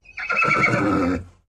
Whinnies
Horse Whinnies & Blows 5